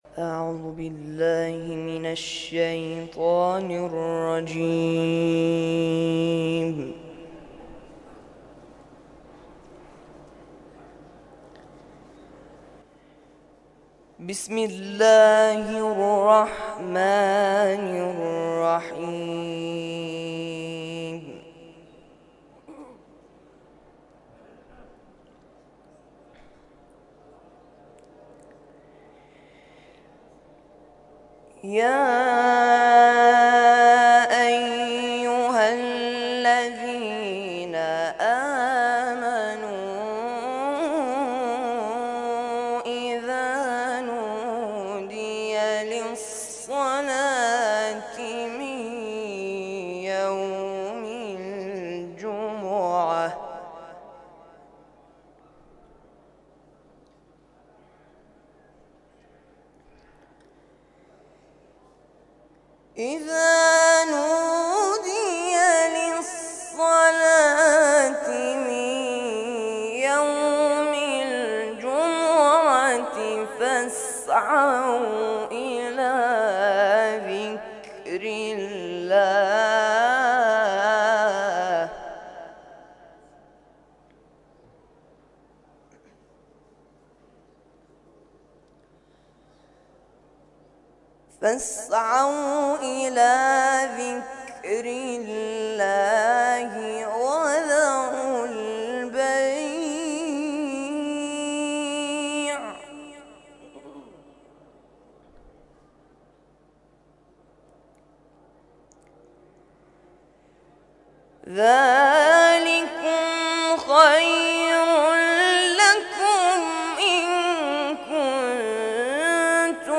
نماز جمعه